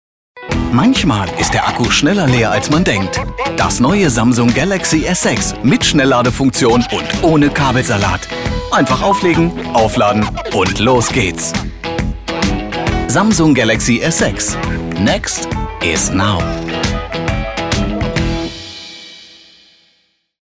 plakativ
Mittel minus (25-45)
Commercial (Werbung)